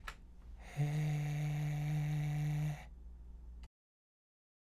過去にやってきたパーの声と比べ、「詰まった感覚が減った、より息漏れ感のある音色」にしていく練習です。
OKのパーの声